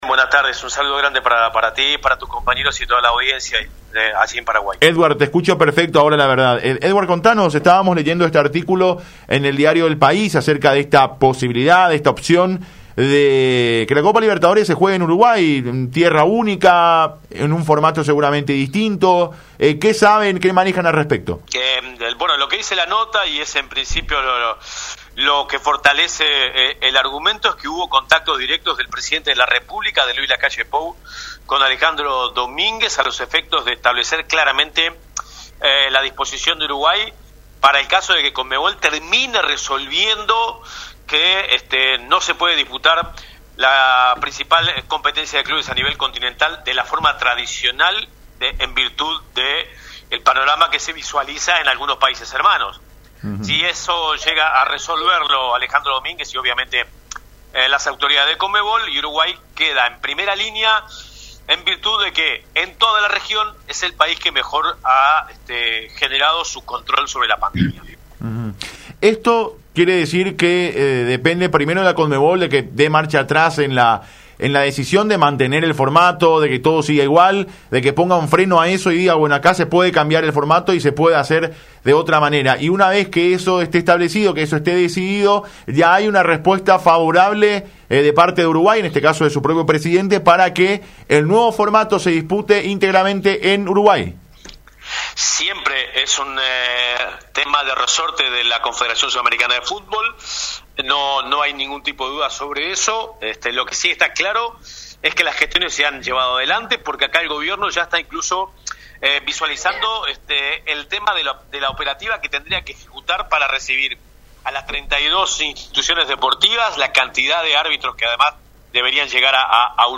En ese sentido, el comunicador manifestó que el gobierno está considerando esta posibilidad, analizando primeramente la operatividad logística. De hecho, se mencionaron tres probables sedes para continuar el torneo, siendo estas Montevideo, Punta del Este y Colonia.